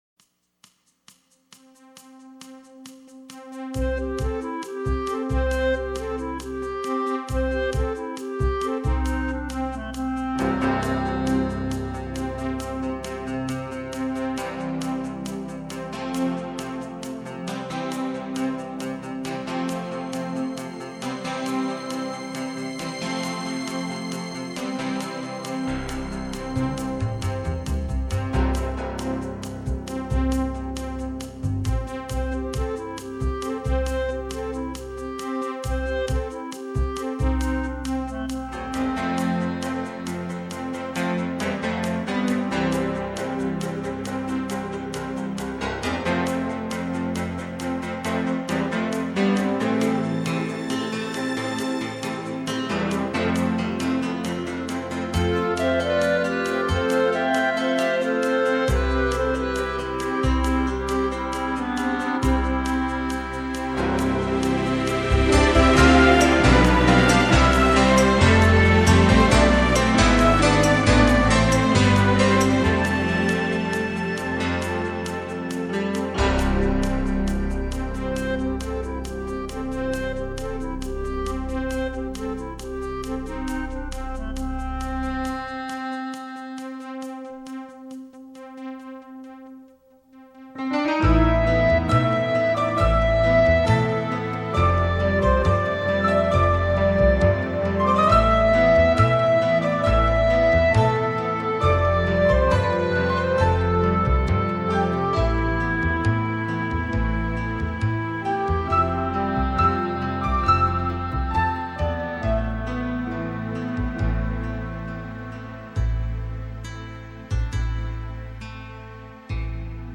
Soundtrack, Score, Orchestral